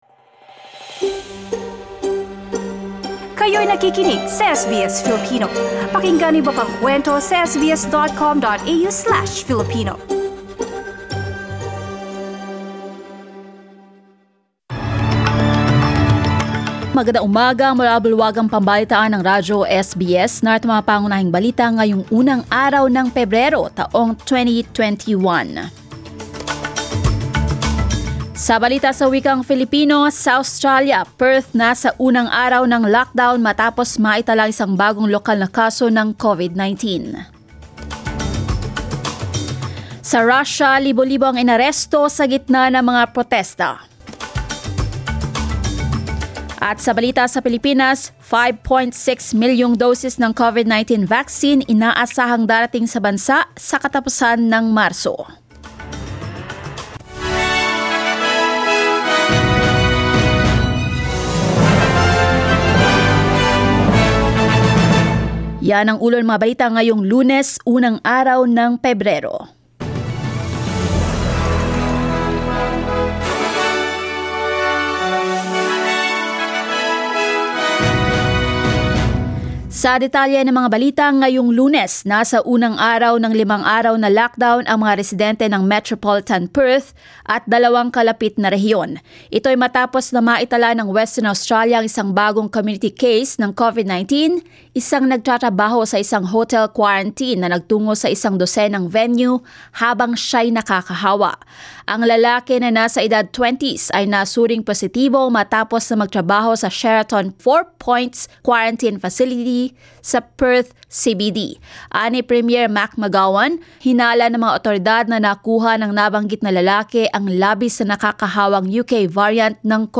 News highlights Perth, Western Australia enters hard lockdown after recording one locally acquired case of COVID-19. Thousands arrested during protests in Russia. Philippines expects 5.6 million COVID-19 vaccine doses to arrive by end-March.